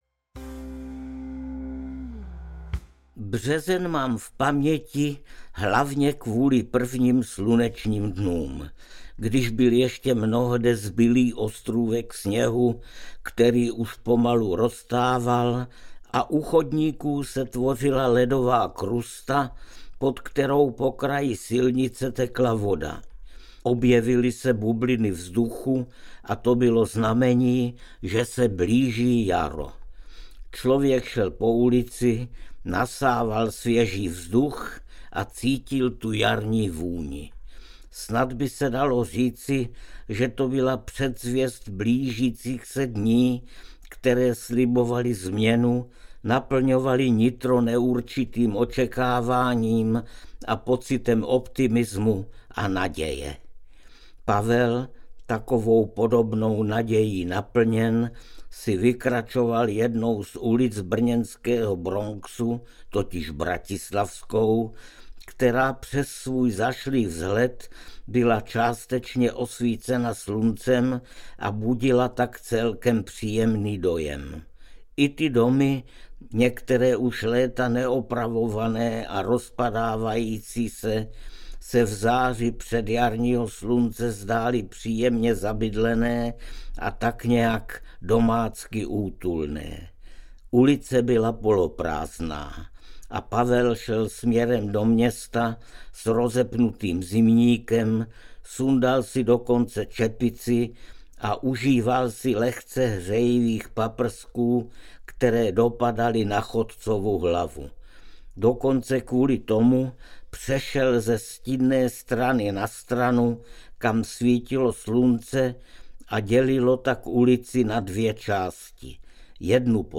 Brno, ty město mých snů audiokniha
Ukázka z knihy
• InterpretArnošt Goldflam